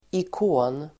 Uttal: [ik'å:n]